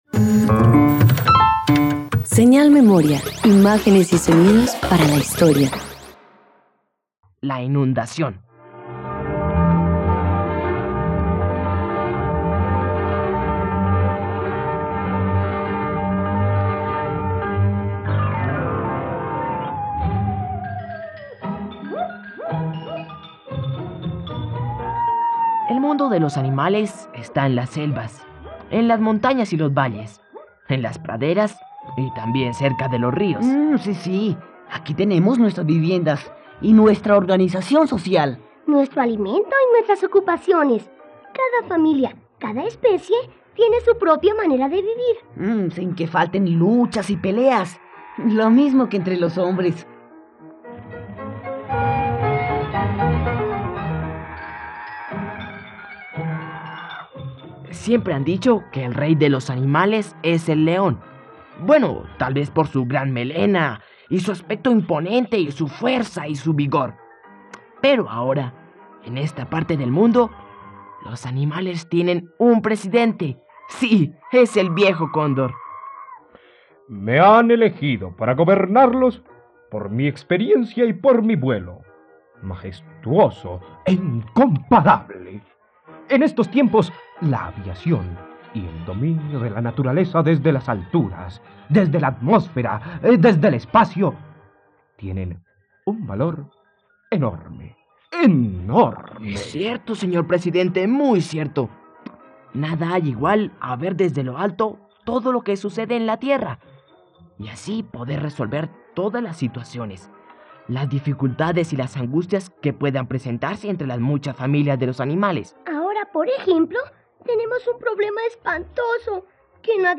..Radioteatro. Escucha la adaptación de la obra "La inundación" del director colombiano José Pulido Téllez en la plataforma de streaming RTVCPlay.